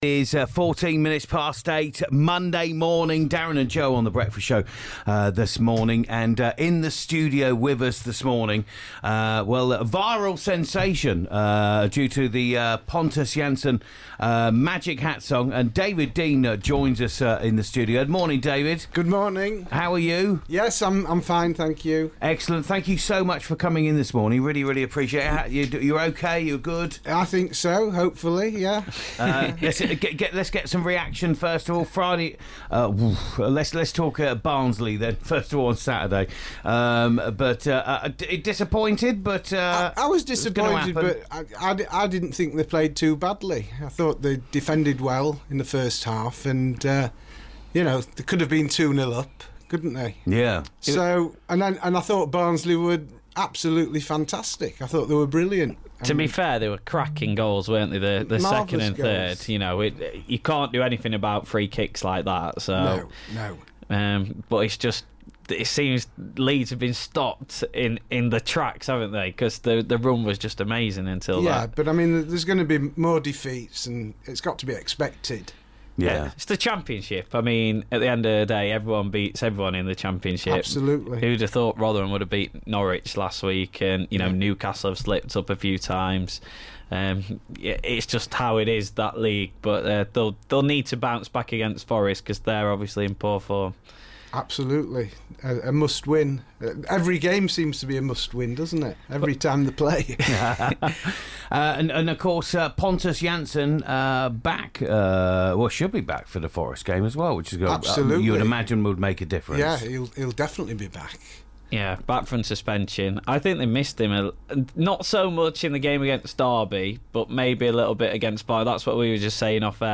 Pontus Jansson Song FULL INTERVIEW